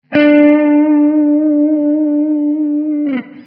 wibrato 1
Technika ta polega na delikatnym naprzemiennym podwyższaniu i obniżaniu wysokości dźwięku - chodzi o minimalne podciągnięcie struny i następnie jej odpuszczenie.
wibrato.mp3